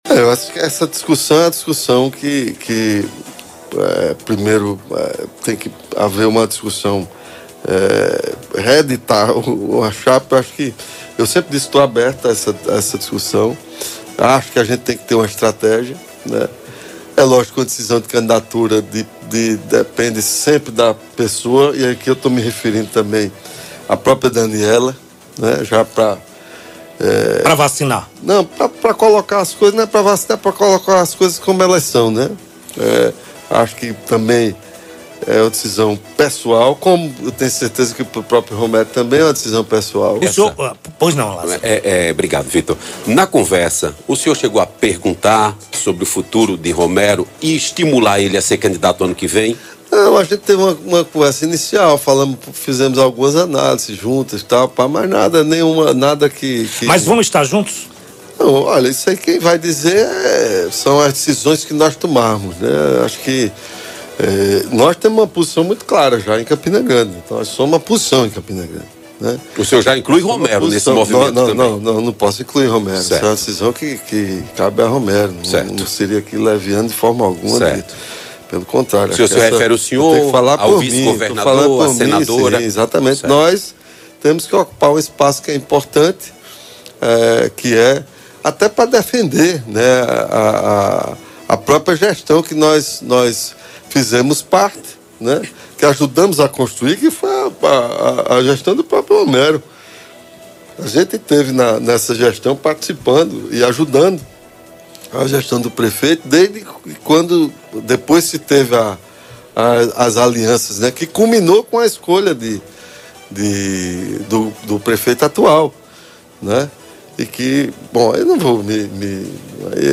“Devemos cuidar do nosso movimento e da nossa aliança estadual. Vou conversar com o governador João Azevêdo para avaliar as cidades em que podemos lançar candidaturas, considerando a viabilidade. Também terei uma conversa com Hugo Motta, sabendo que, em algumas cidades, pode haver dificuldade para conciliar, mas é algo que precisamos administrar”, concluiu durante entrevista à Rádio Correio FM.